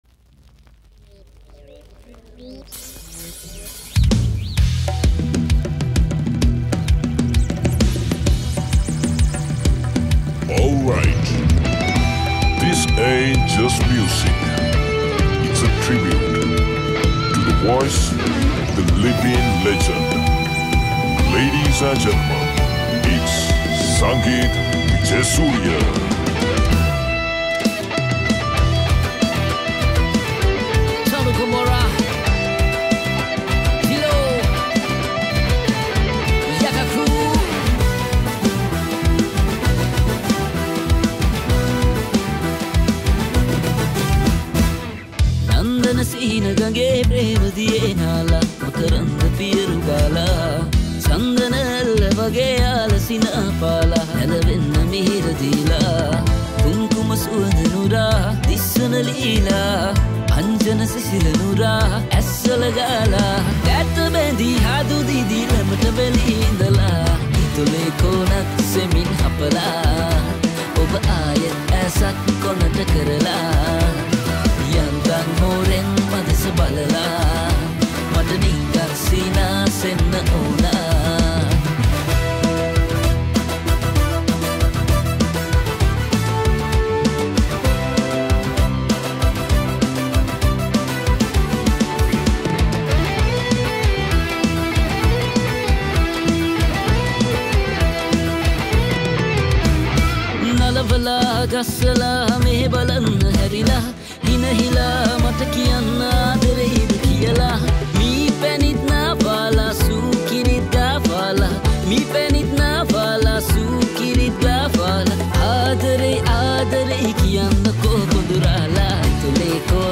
Releted Files Of Sinhala Band Medley Songs